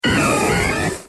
Cri d'Empiflor dans Pokémon X et Y.